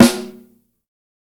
TC3Snare12.wav